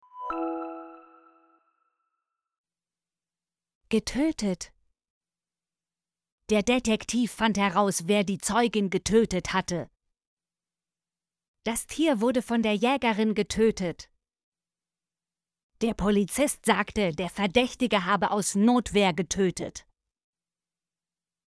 getötet - Example Sentences & Pronunciation, German Frequency List